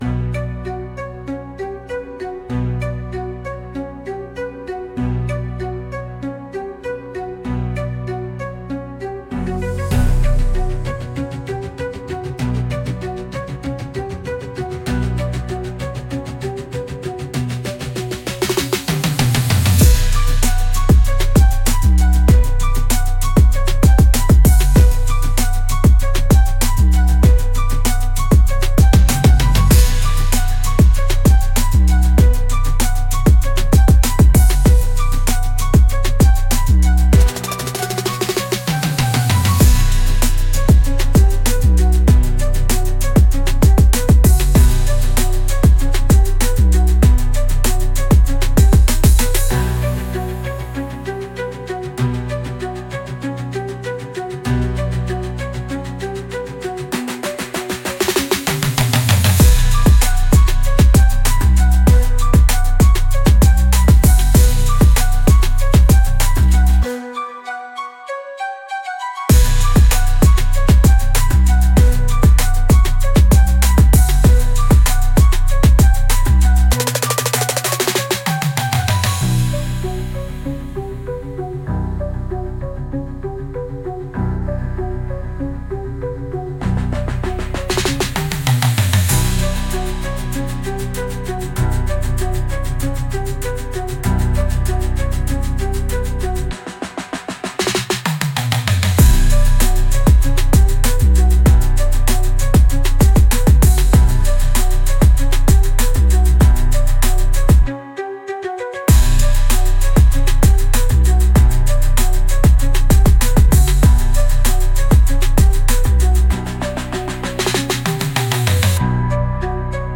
Instrumental - Burn in the Bassline - 2.13 mins